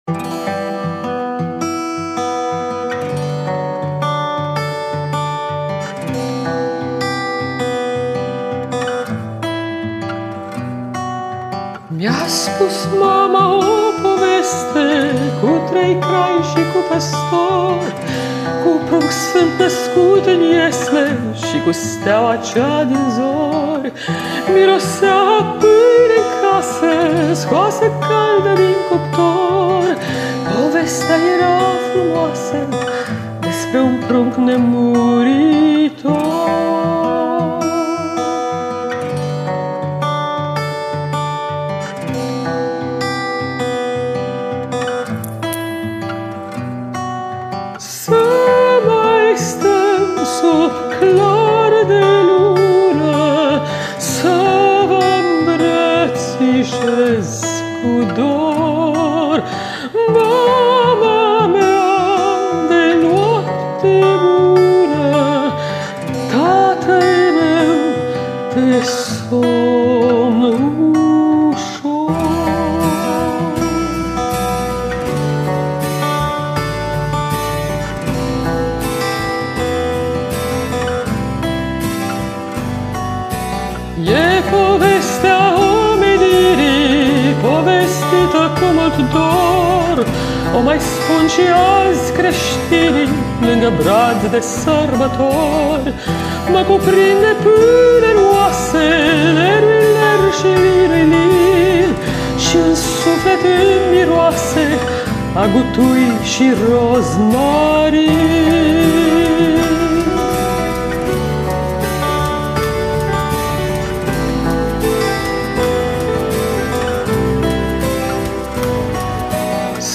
La realizarea acestui colind au contribuit